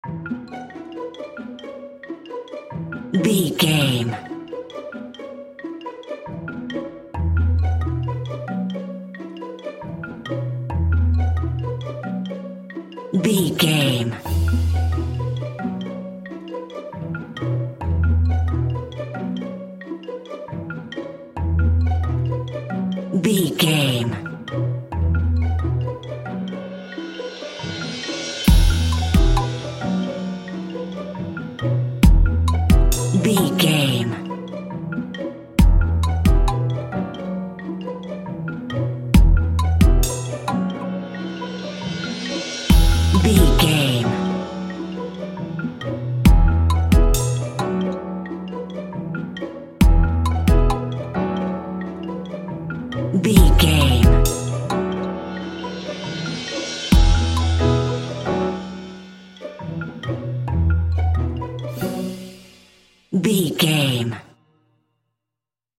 Mixolydian
percussion
strings
double bass
synthesiser
cheerful
quirky